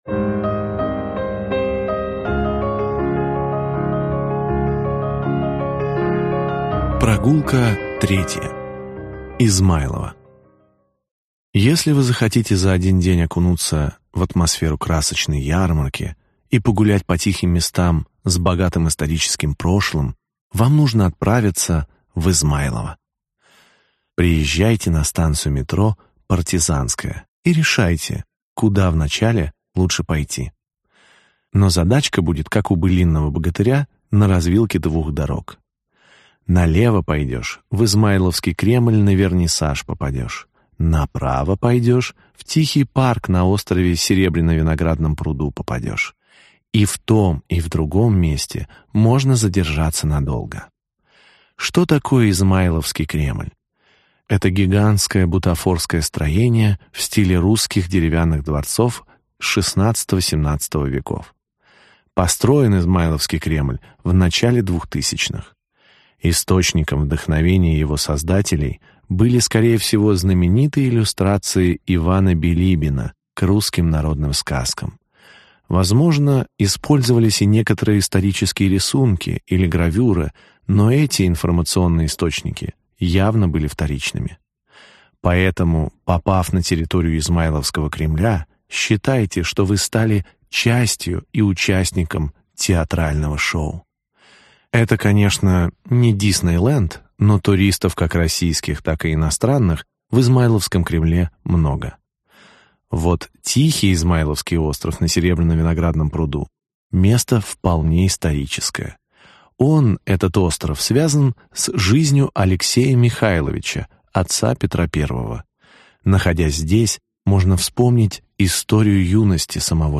Аудиокнига 8 заповедных мест в Москве, куда можно доехать на метро. Глава 3. Измайлово | Библиотека аудиокниг